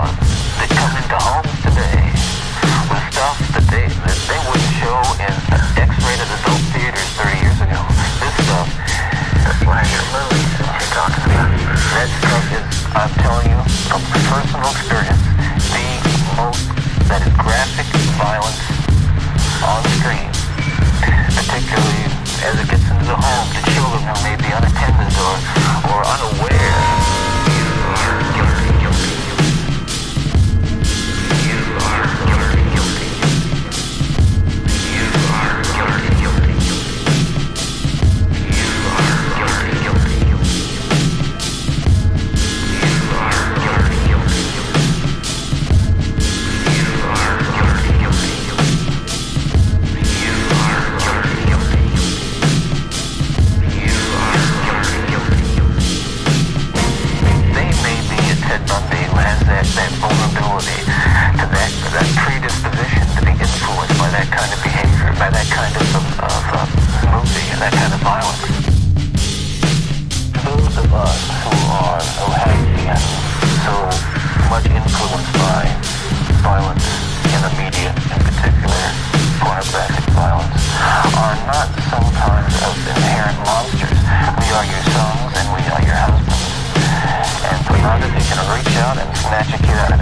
Remix Instrumental